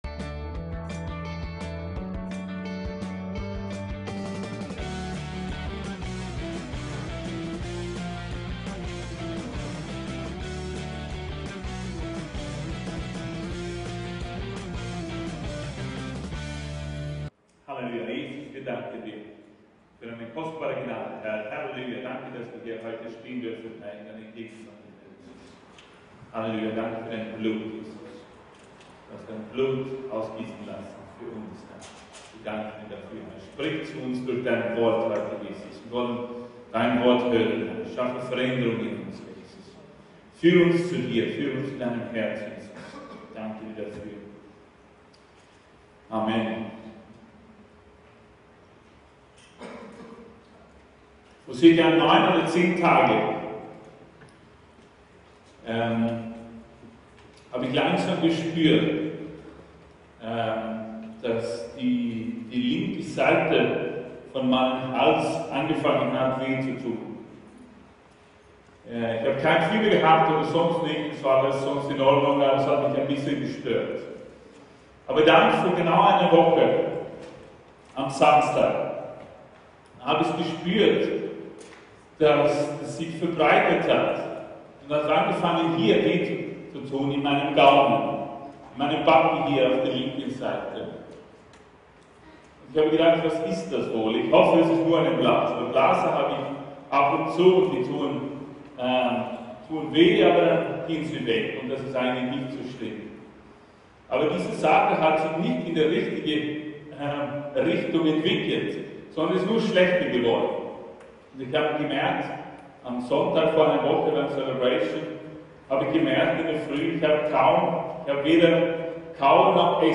SCHRITTE ZUR WIEDERBELEBUNG ~ VCC JesusZentrum Gottesdienste (audio) Podcast